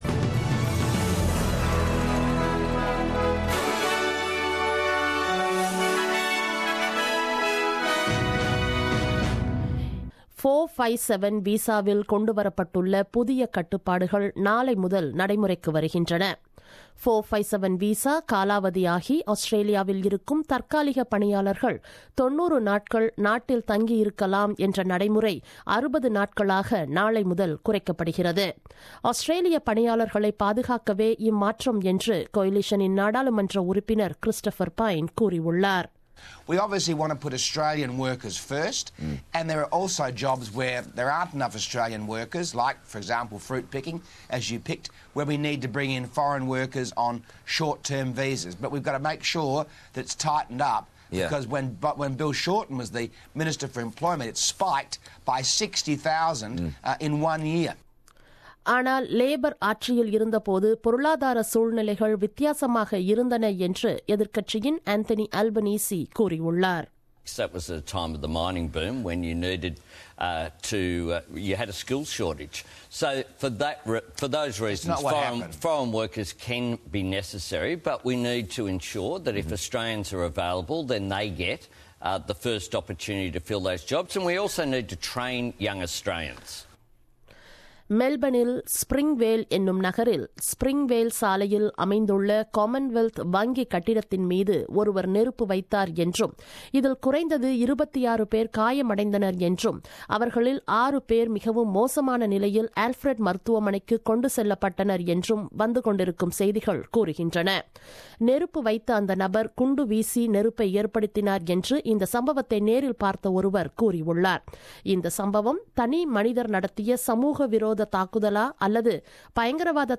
The news bulletin broadcasted on 18 Nov 2016 at 8pm.